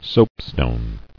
[soap·stone]